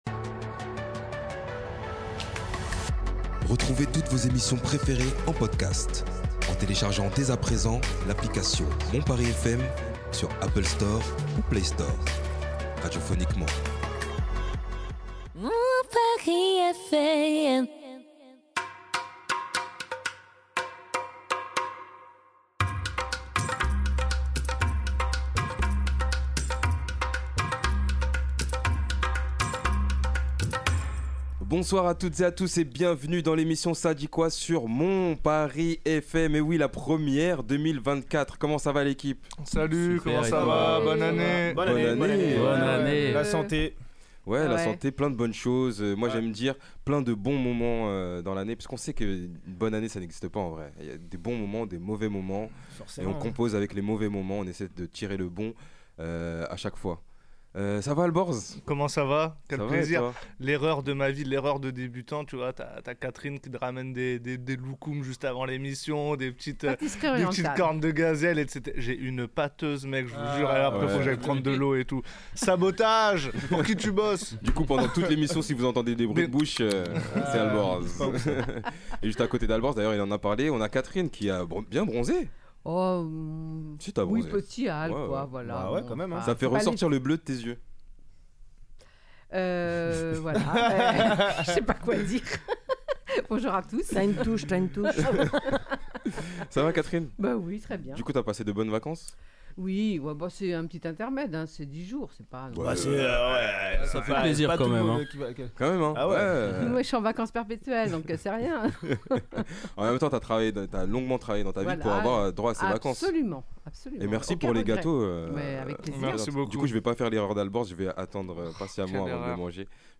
Intelligence artificielle, pour ou contre ? (Débat de la semaine)- Le Saviez-vous !?